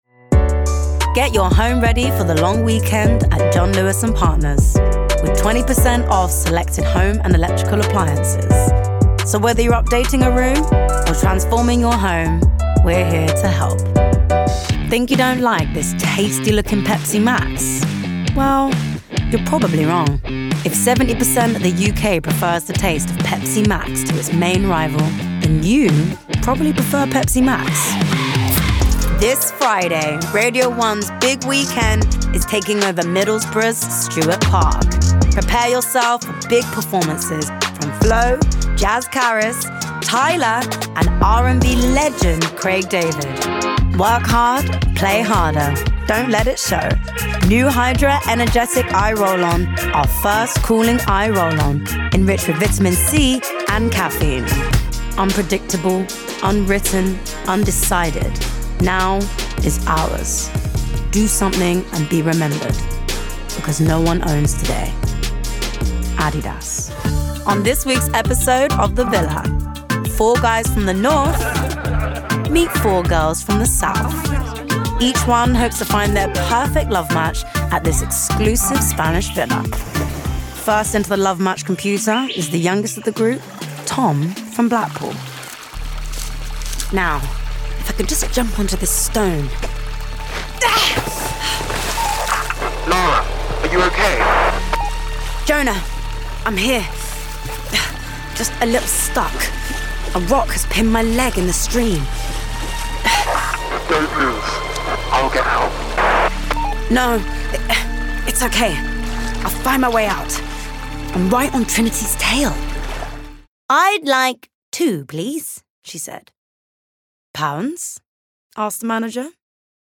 London, RP ('Received Pronunciation')
Cool Husky Friendly